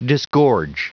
Prononciation du mot disgorge en anglais (fichier audio)
Prononciation du mot : disgorge